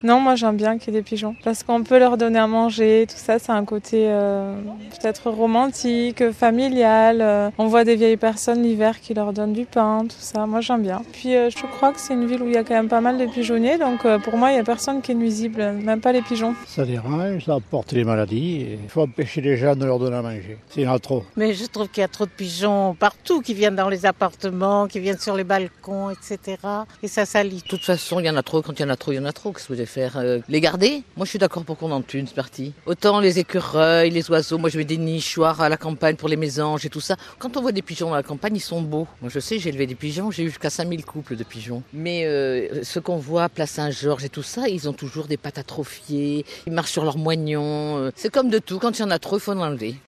toulouse-pigeons-micro-trottoir.mp3